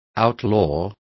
Complete with pronunciation of the translation of outlaw.